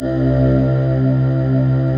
Index of /90_sSampleCDs/Optical Media International - Sonic Images Library/SI1_SlowOrchPad/SI1_SlowMelowPad